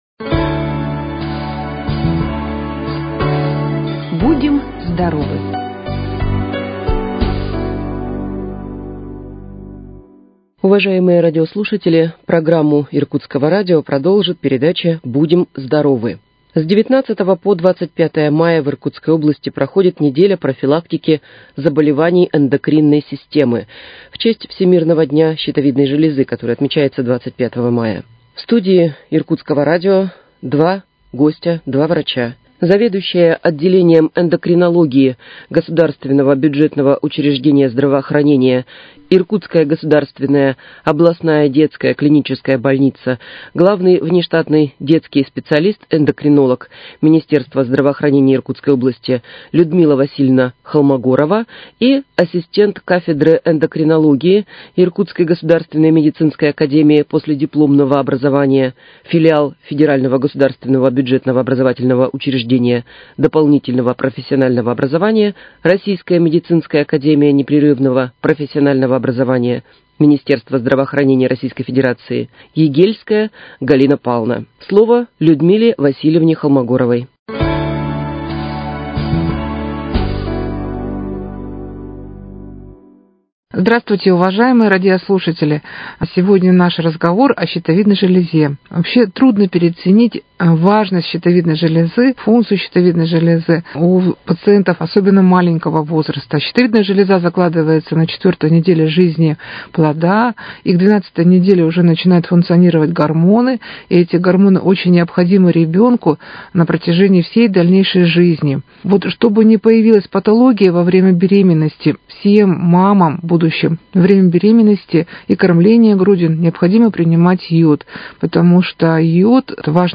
В студии Иркутского радио